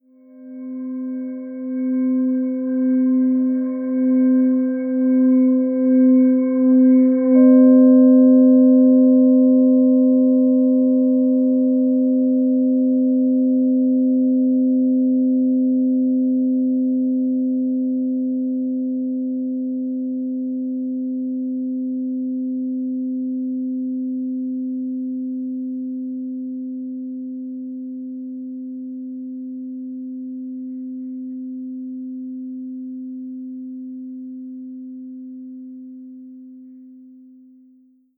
10" : C Note : 432Hz : Perfect Pitch : Root Chakra Bowl : Stability | Fae, Flower & Stone